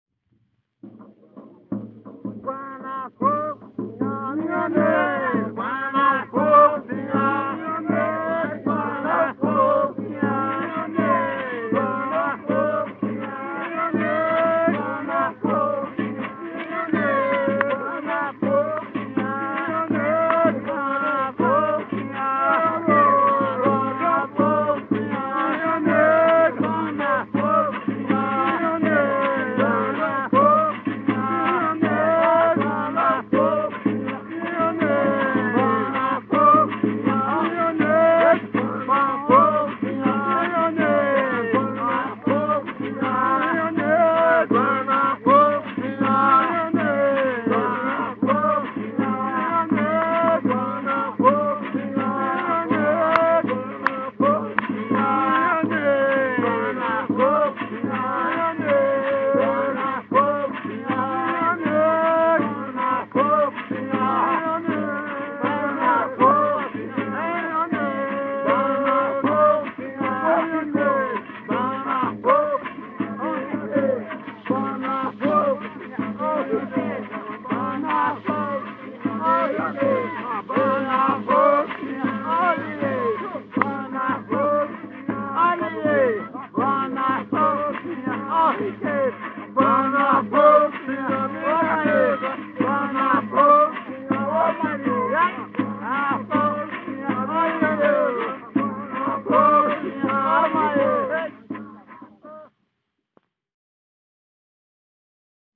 Coco embolada